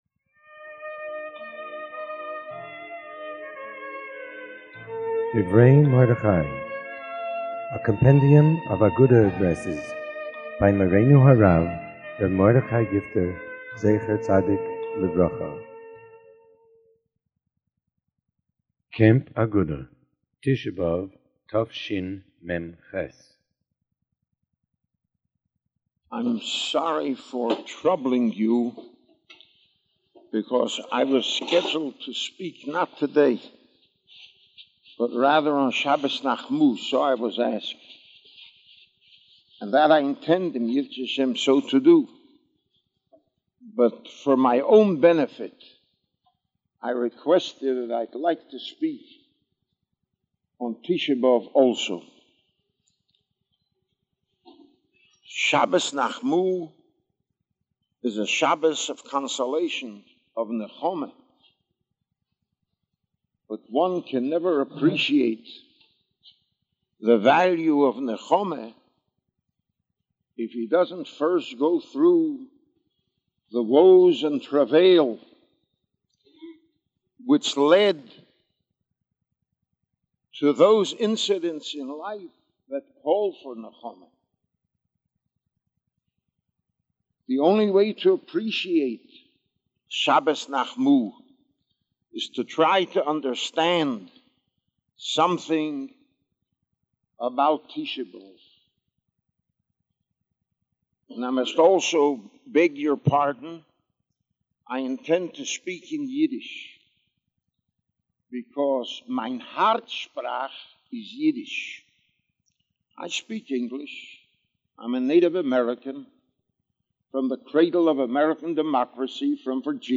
Tisha B'Av - Camp Agudah 1988
Recent Sermons
CampAgudahTishaBAv1988Address.mp3